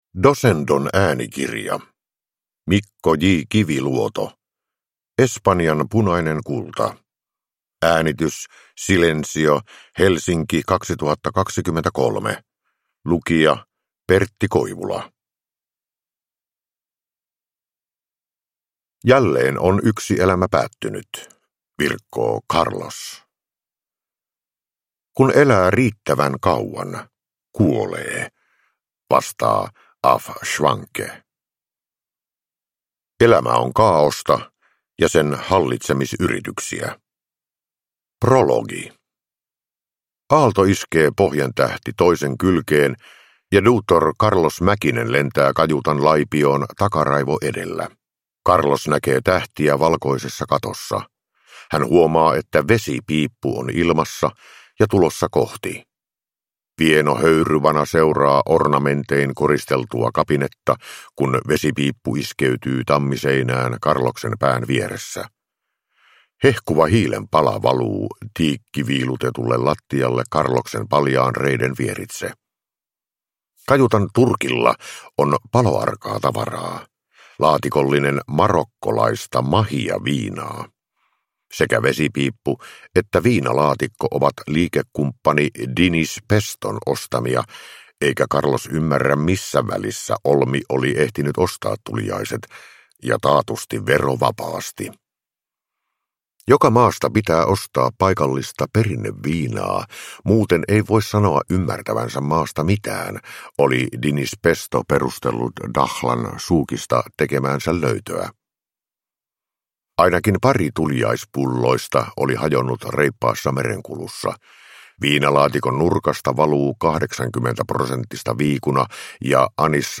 Espanjan punainen kulta – Ljudbok – Laddas ner
Uppläsare: Pertti Koivula